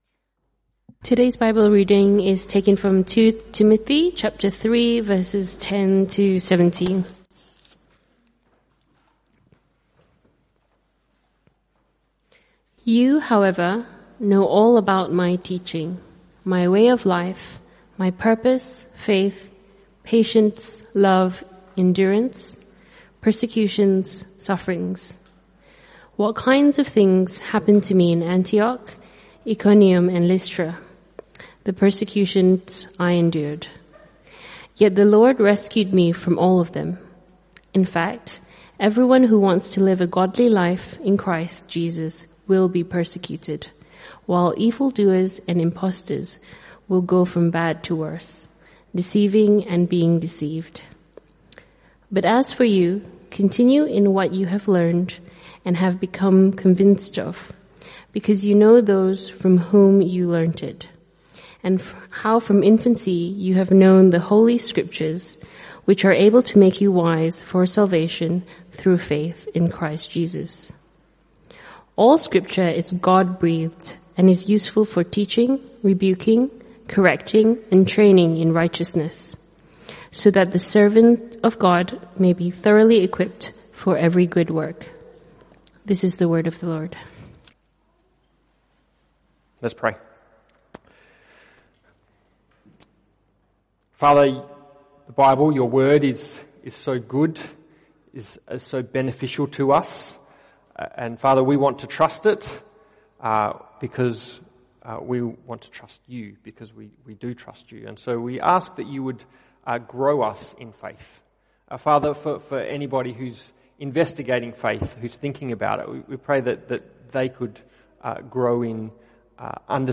Passage: 2 Timothy 3:10-17 Type: Sermons CBC Service